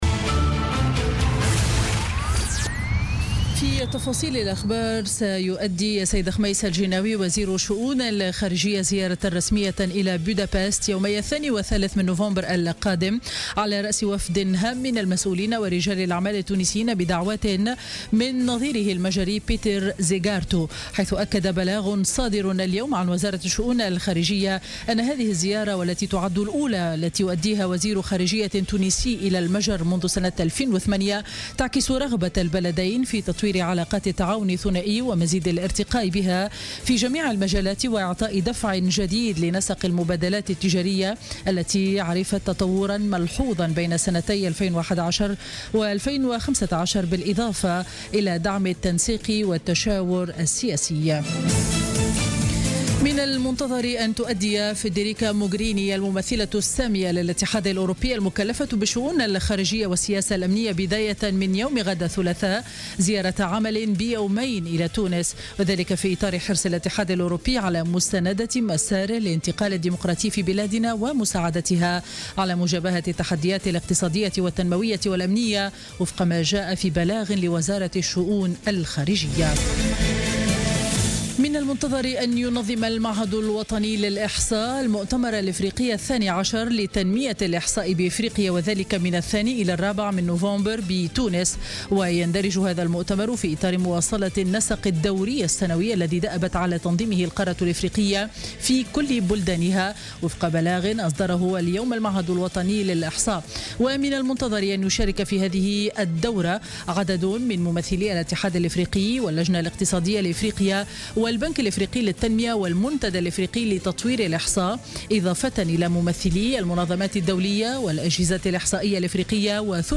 نشرة أخبار السابعة مساء ليوم الاثنين 31 أكتوبر 2016